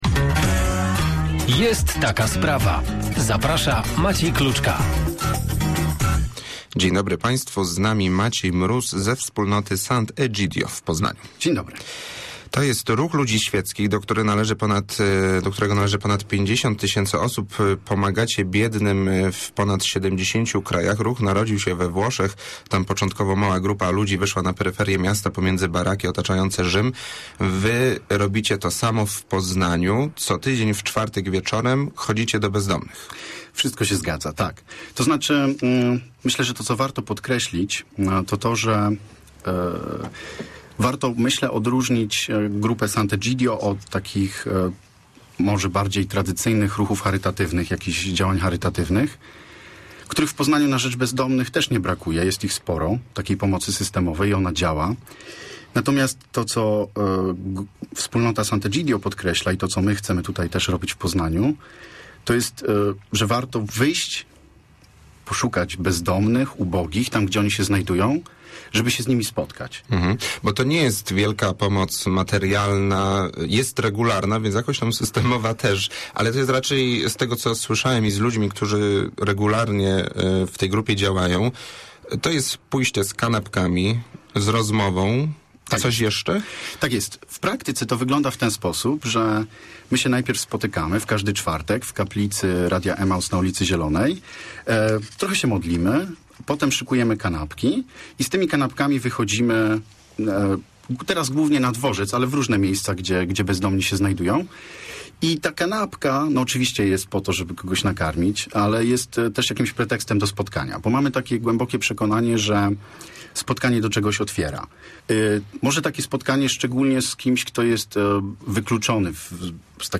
hwn4g81qa7s8chu_rozmowa_kanapki_dla_bezdomnych.mp3